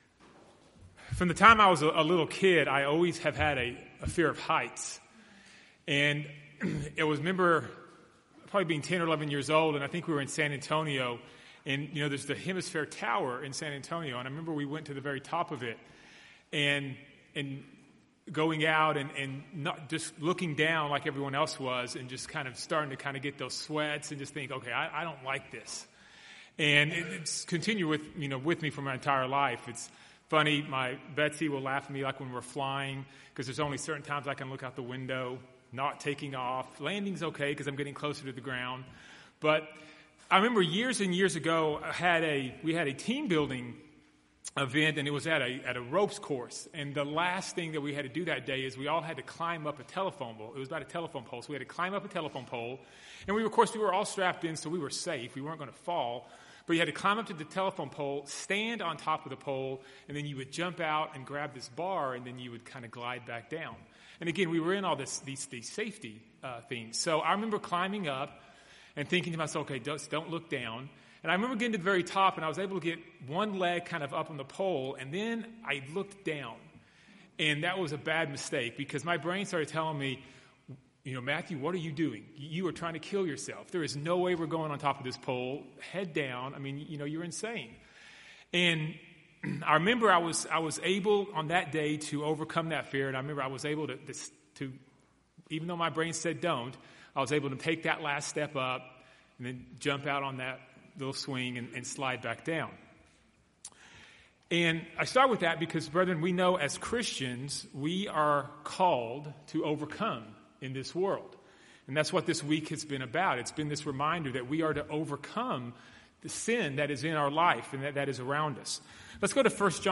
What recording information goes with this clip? Given in Dallas, TX Fort Worth, TX